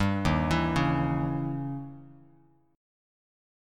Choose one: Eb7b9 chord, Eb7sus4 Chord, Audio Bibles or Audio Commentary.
Eb7b9 chord